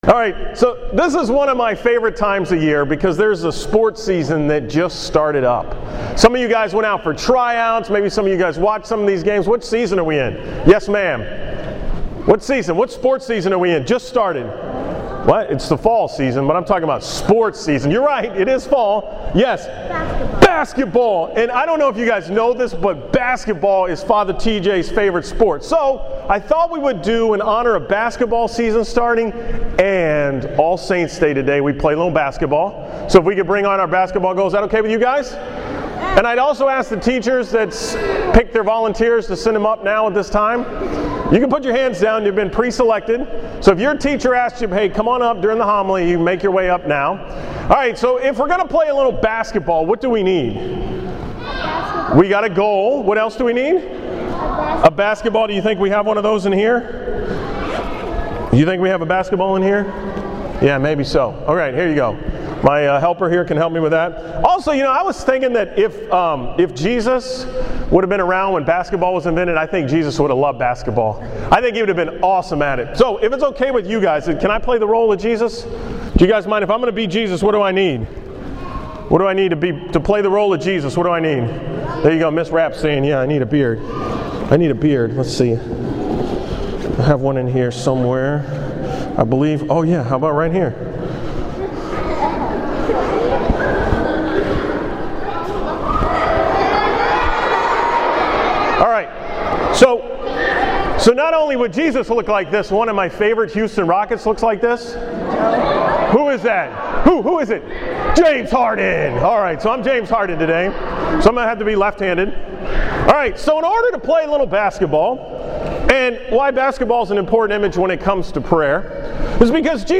From the School Mass on November 1, 2013
Category: 2013 Homilies, School Mass homilies, Sports themed homilies